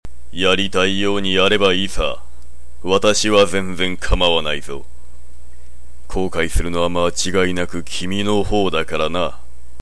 ４０歳（推定）／男
■　Voice　■